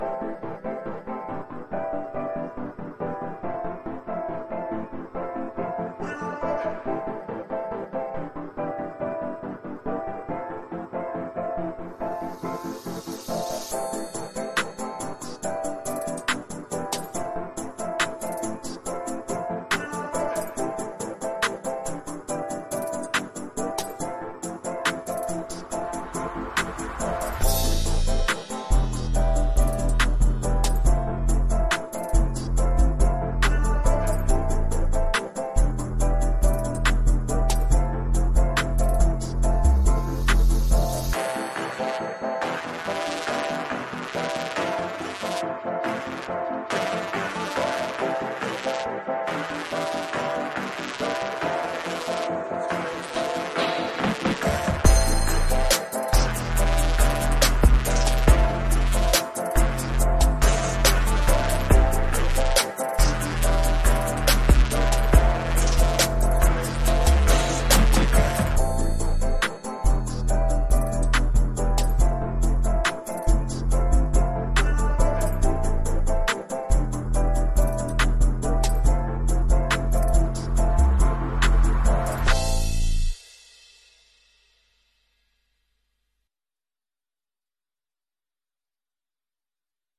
Trap_0327_7.mp3